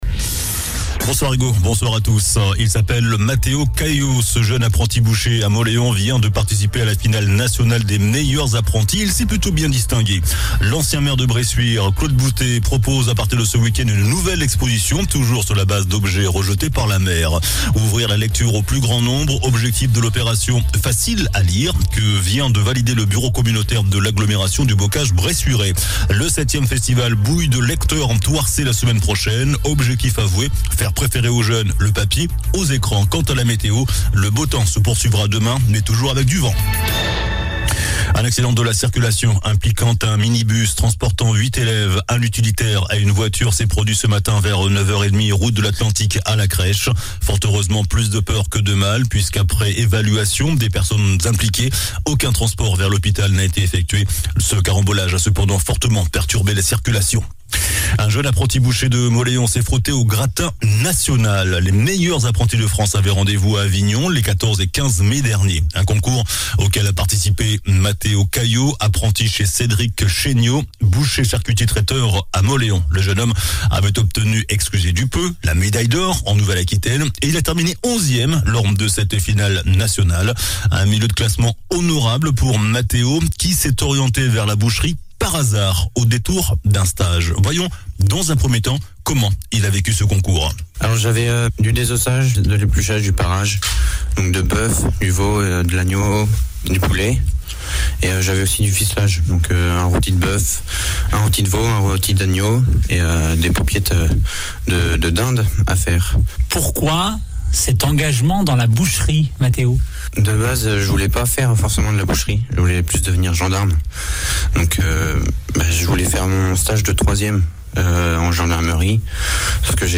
JOURNAL DU MERCREDI 24 MAI ( SOIR )